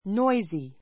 noisy A2 nɔ́izi ノ イズィ 形容詞 比較級 noisier nɔ́iziə r ノ イズィア 最上級 noisiest nɔ́iziist ノ イズィエ スト 騒 さわ がしい, そうぞうしい, うるさい ⦣ noise＋-y. 反対語 quiet （静かな） noisy children noisy children 騒いでいる子供たち What a noisy class you are!